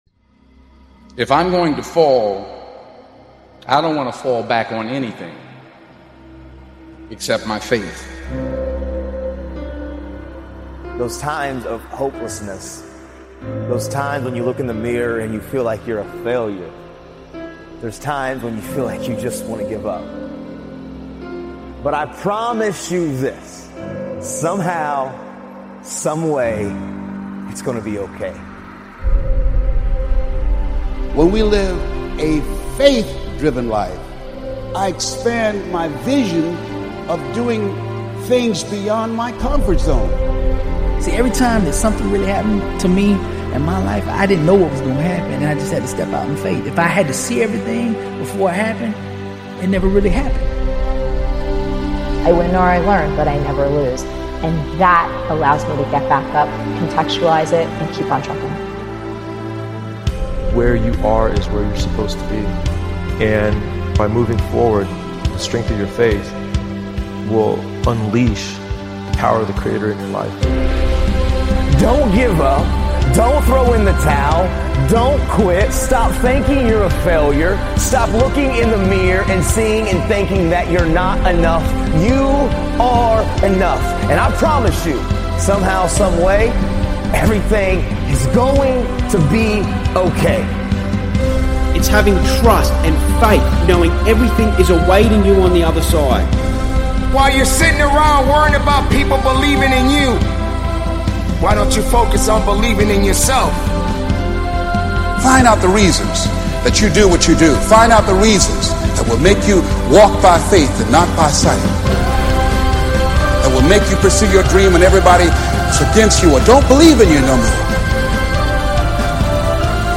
Speakers: Denzel Washington